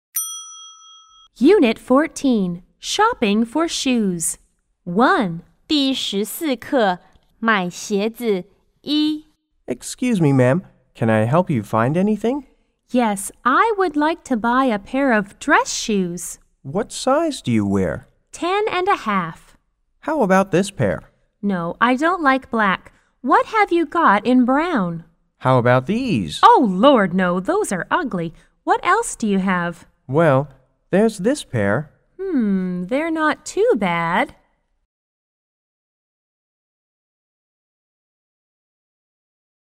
在线英语听力室购物英语口语一本通 Unit14:买鞋子(1)的听力文件下载, 《购物英语口语一本通》收录了英语口语中最常用的购物英语情景对话，是学习英语口语，提高英语口语交际水平的好帮手。